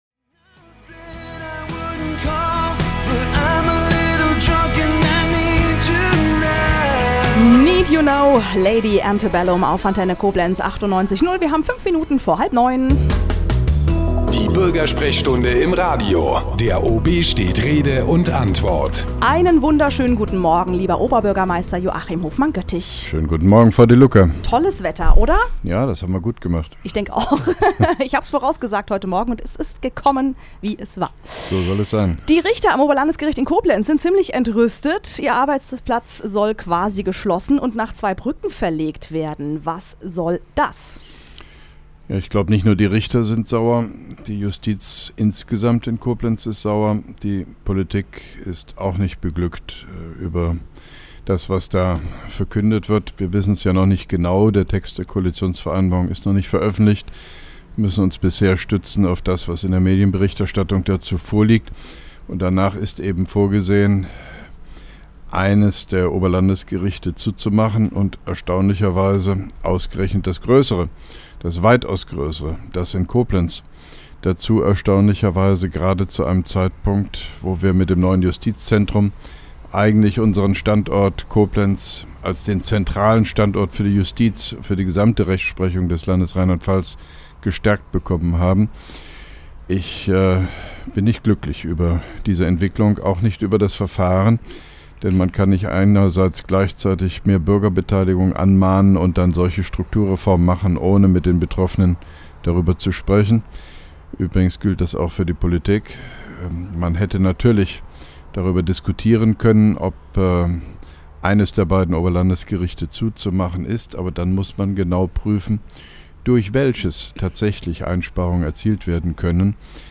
(1) Koblenzer Radio-Bürgersprechstunde mit OB Hofmann-Göttig 03.05.2011